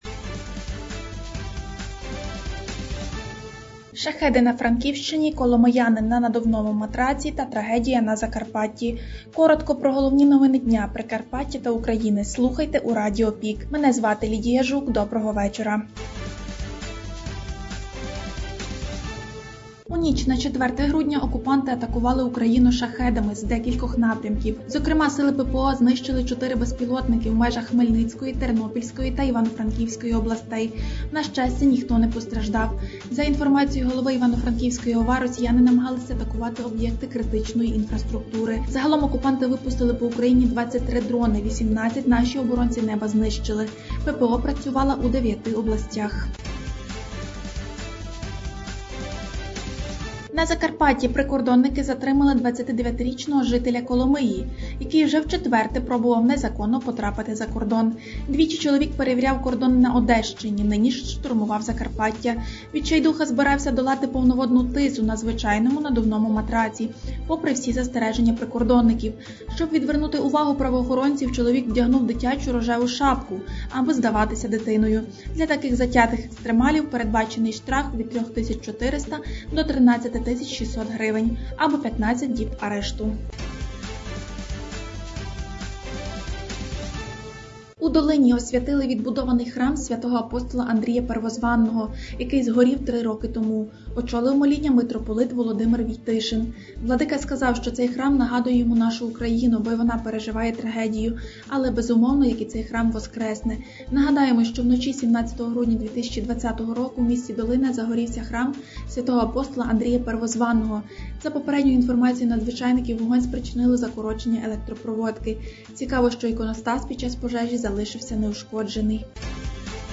Радіо ПІК: головні новини Прикарпаття та України за четверте грудня (ПРОСЛУХАТИ)
Пропонуємо Вам актуальне за день у радіоформаті.